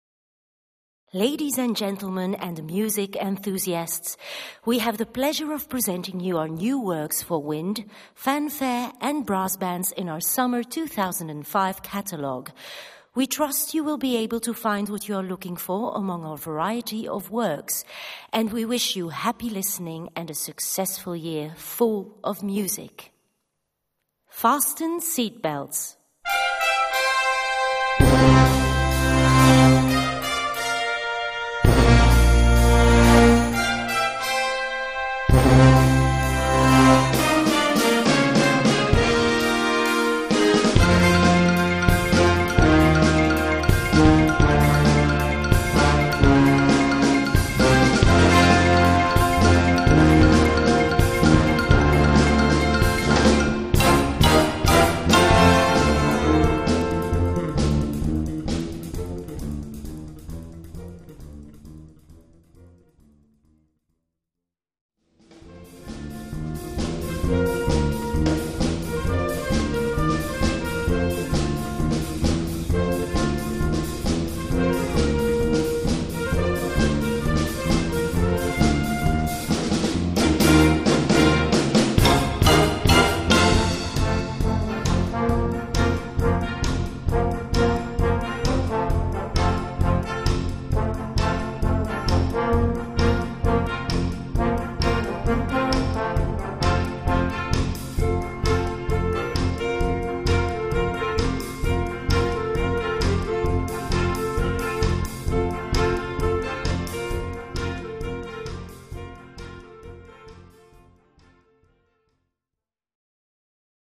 Gattung: Konzertante Blasmusik
Besetzung: Blasorchester